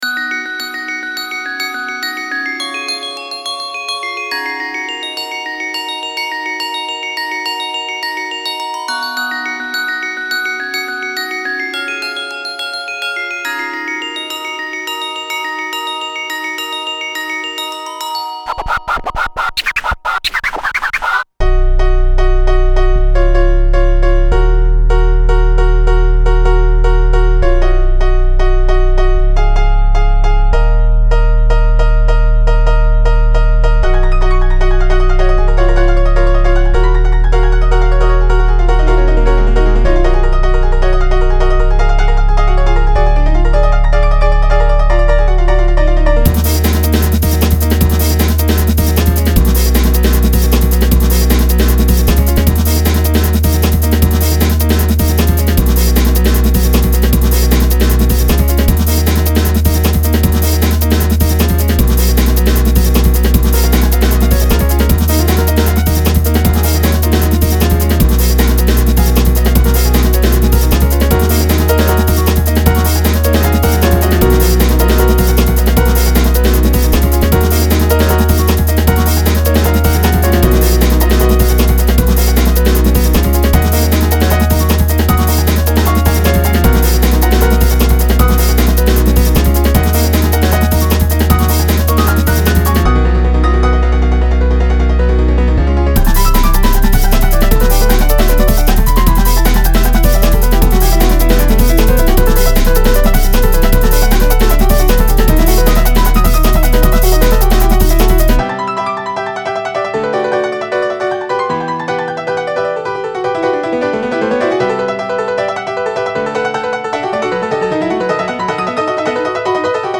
inst
remix